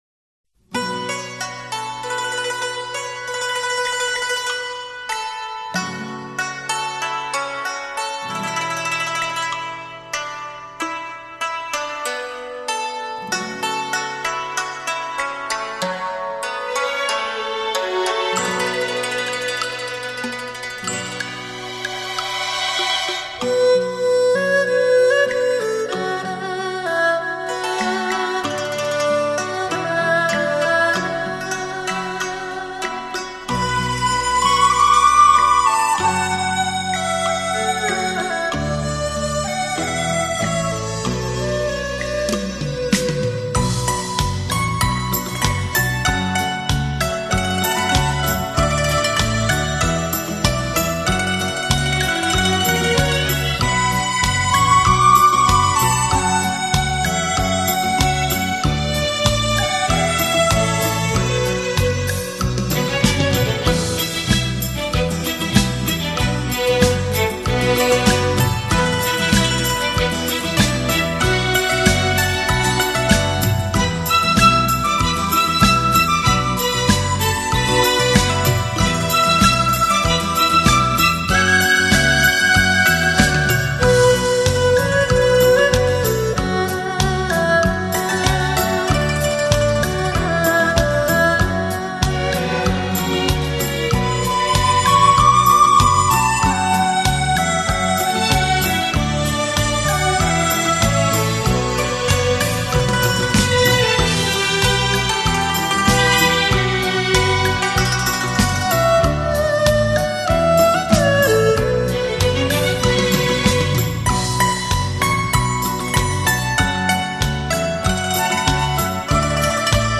古筝、二胡、笛子、杨琴、小提琴与乐队的缠绵交响协奏乐章。。。